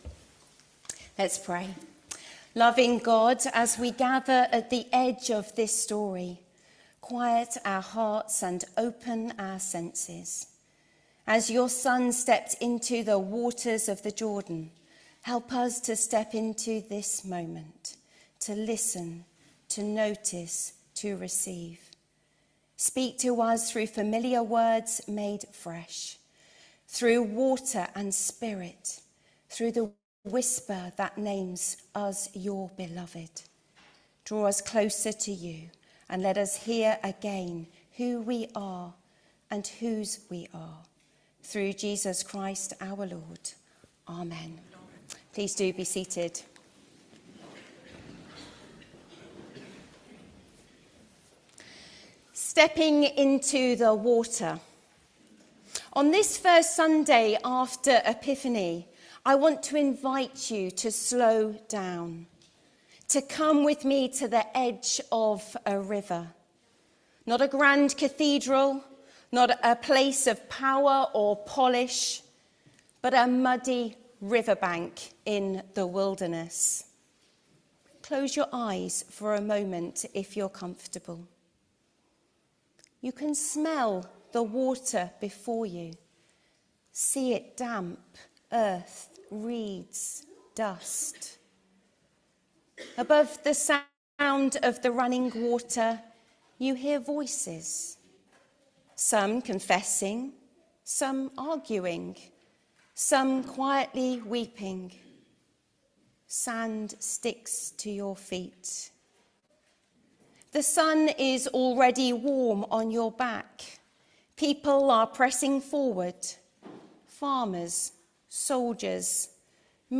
HomeSermonsStep in to the moment